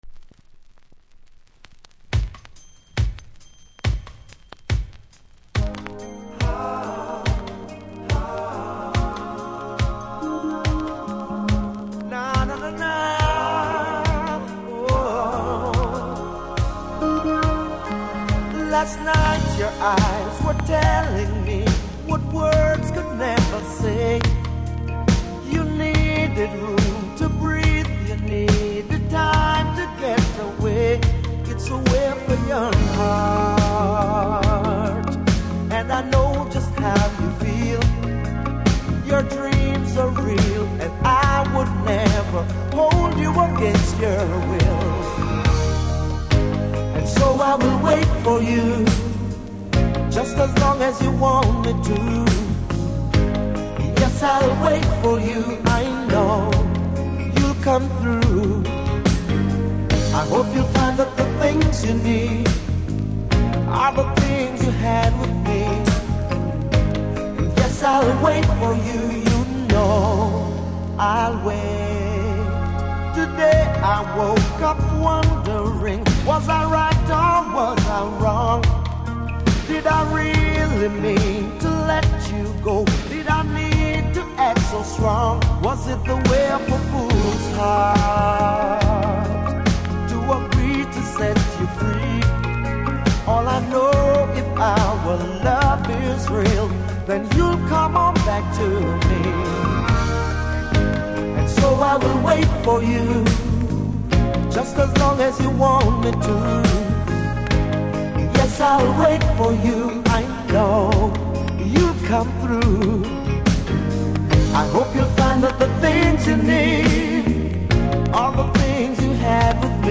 Vinyl
未使用盤で3枚とも同じようなノイズ感じます。